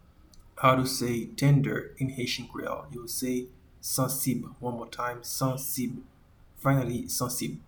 Pronunciation:
Tender-in-Haitian-Creole-Sansib.mp3